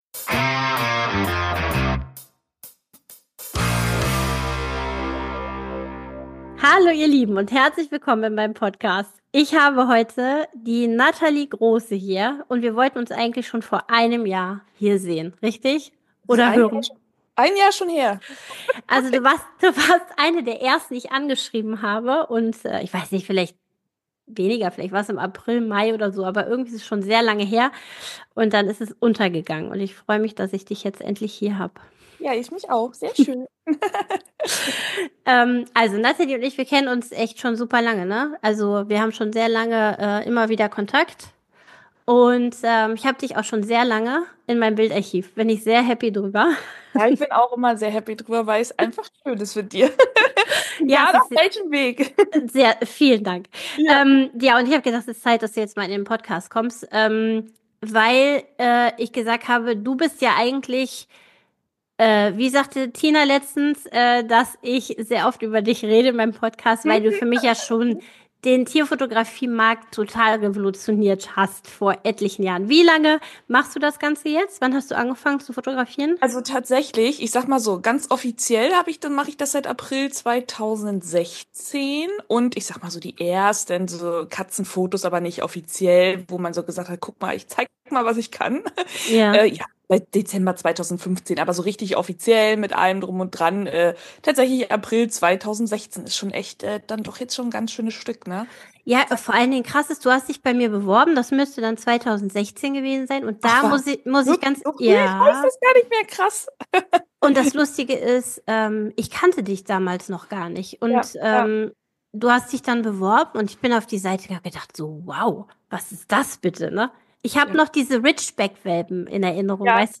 Beschreibung vor 1 Jahr Was für ein Dialog.